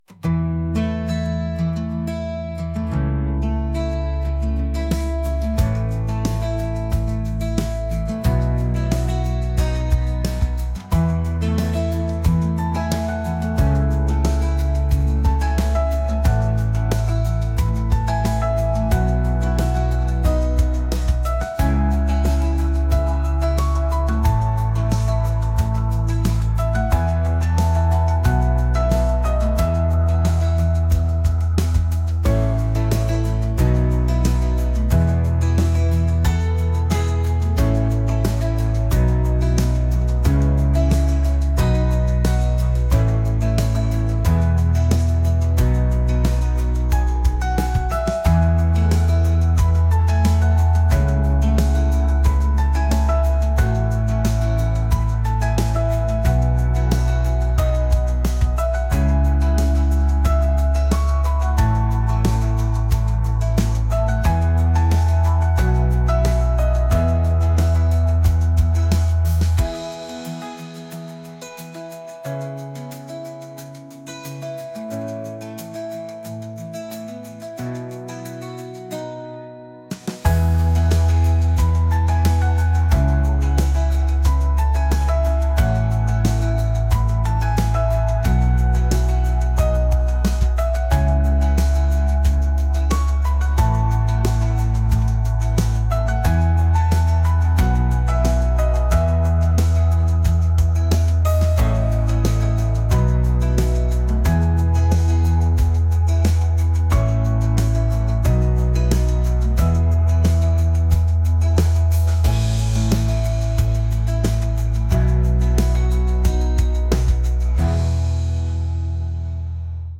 acoustic | pop | indie